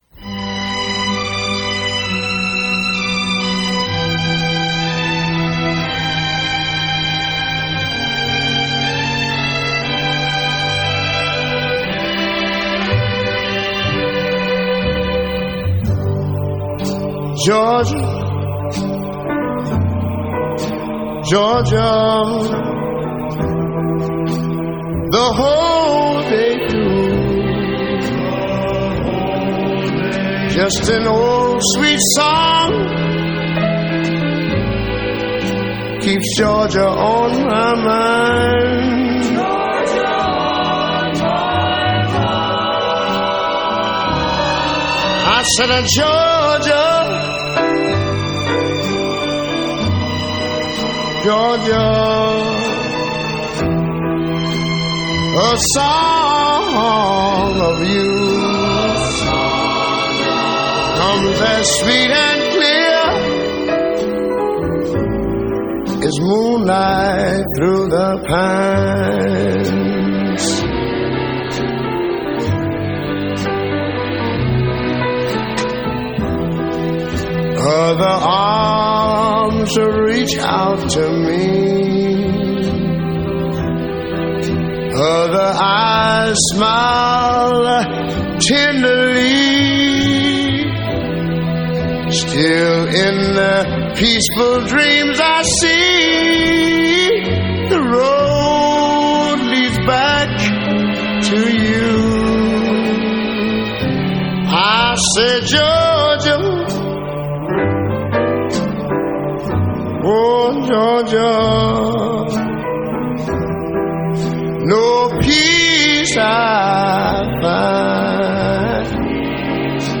Soul, Jazz, Blues